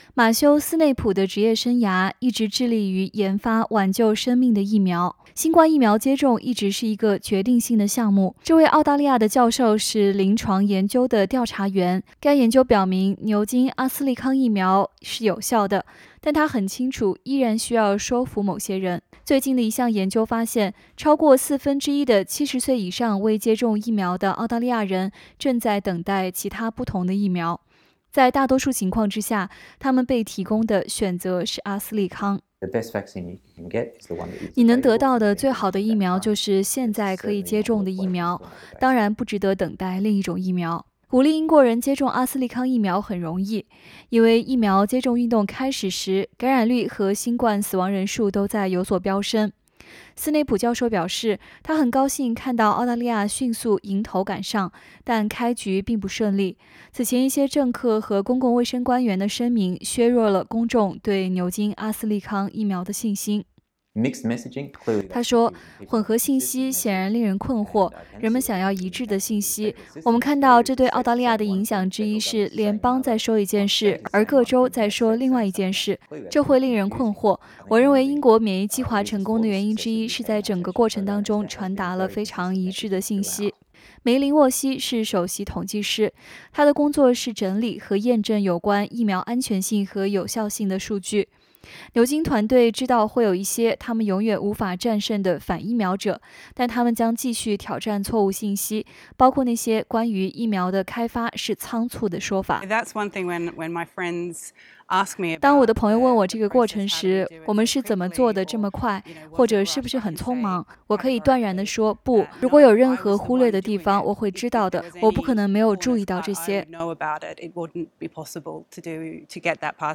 随着Delta变种病毒在全国蔓延，阿斯利康疫苗的创造者已向澳大利亚老年人发出请求，敦促他们不要“等待”辉瑞或Moderna疫苗。SBS 新闻采访了两位在牛津疫苗研发中发挥重要作用的澳大利亚人。他们已经解释了团队的下一步工作以及为什么打击错误信息仍然至关重要。